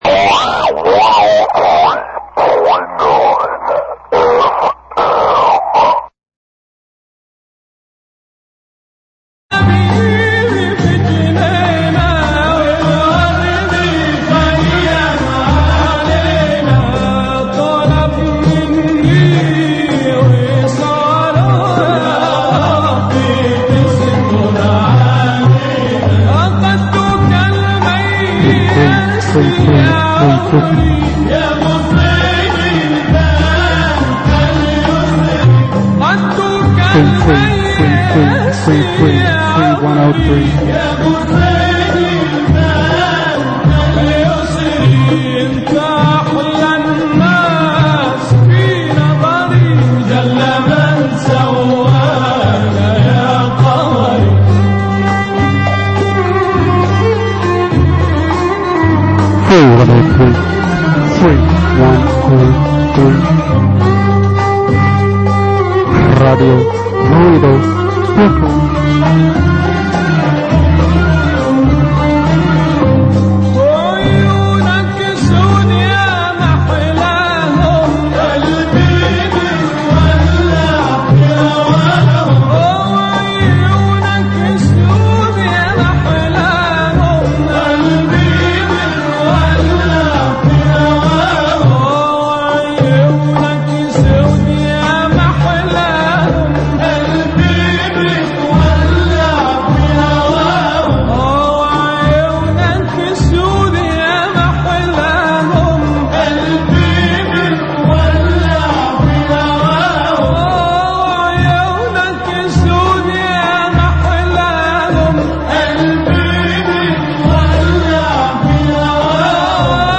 Triangulation_071508 (Audio) Jul 15, 2008 works Triangulation Radio Ruido hosted this show ....an attempt to nav... artists Radio Ruido Weekly show from Radio Ruido live from Brooklyn.